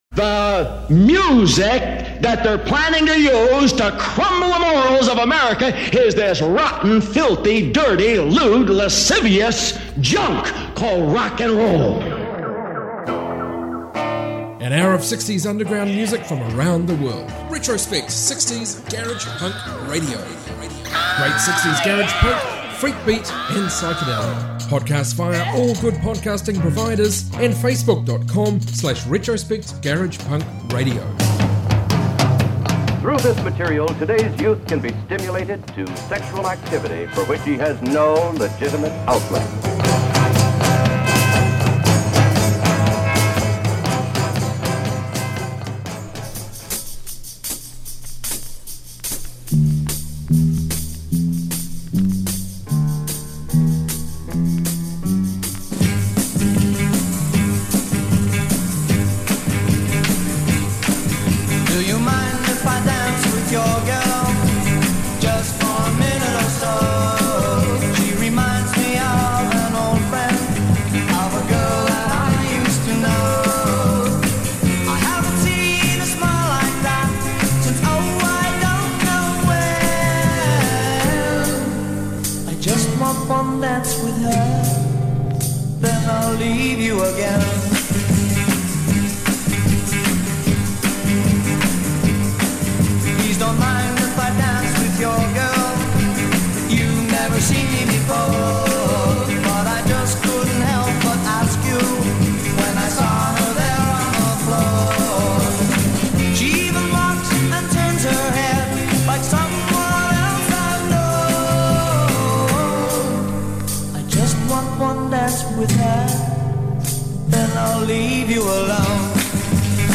60s garage rock punk freakbeat